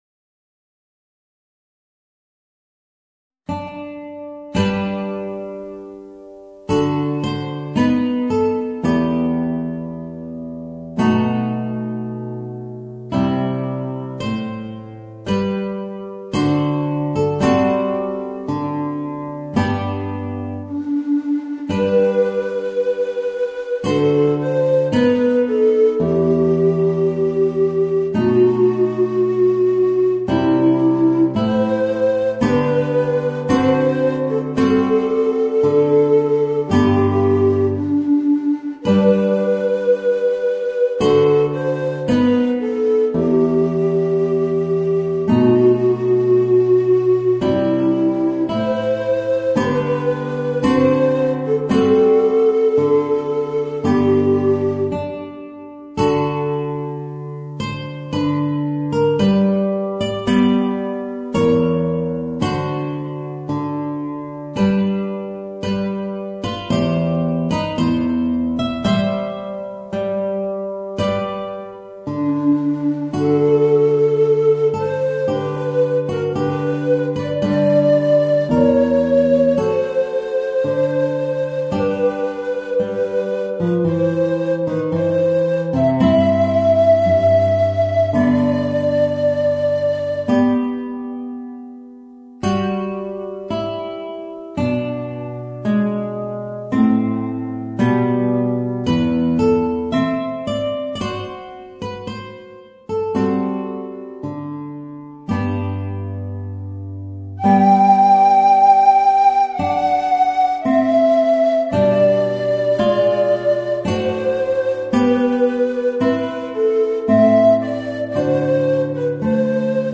Voicing: Guitar and Tenor Recorder